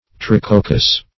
Search Result for " tricoccous" : The Collaborative International Dictionary of English v.0.48: Tricoccous \Tri*coc"cous\, a. [Gr. tri`kokkos with three grains or berries; ?
tricoccous.mp3